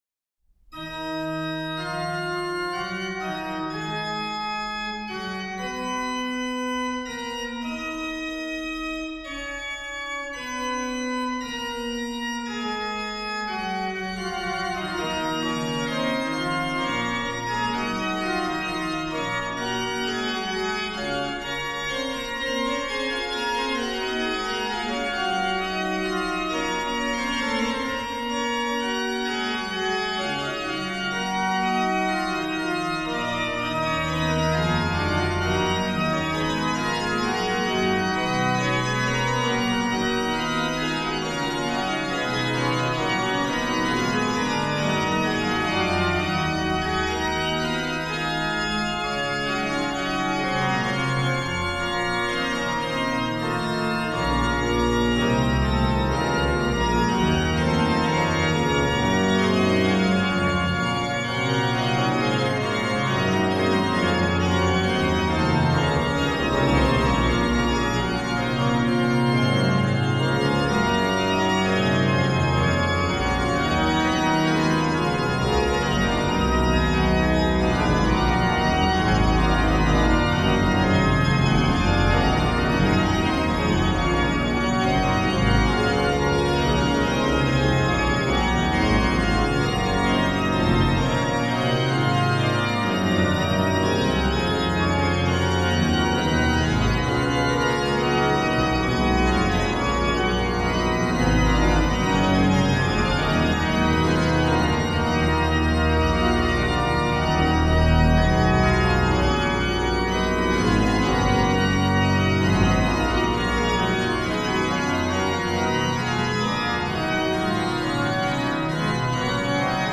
Fuga:
HW: Pr16, Oct8, Ged8, Oct4, Mix, BW/HW
BW: Fl8, Oct4, Oct2, Scharff
Ped: Pr16, Oct8, Oct4, Rausch, Mix, Pos16, Tr8